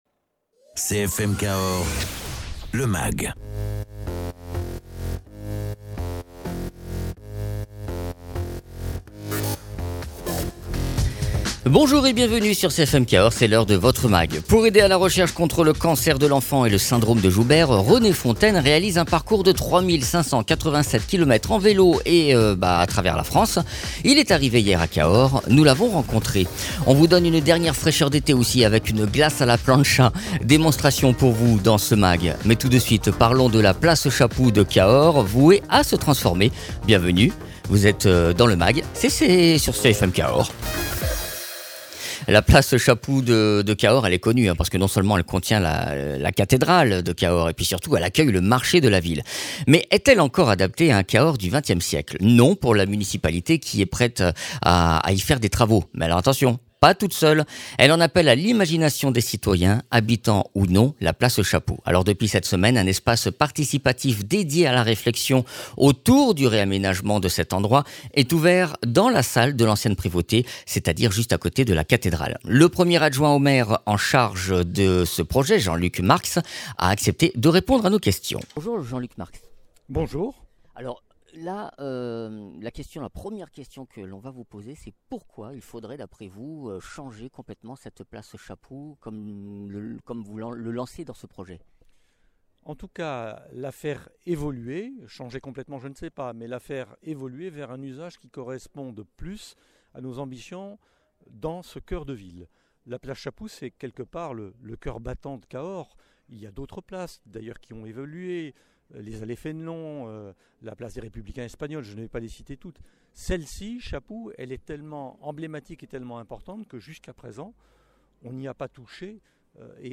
Invité(s) : Jean Luc Marx, Premier adjoint au maire de Cahors.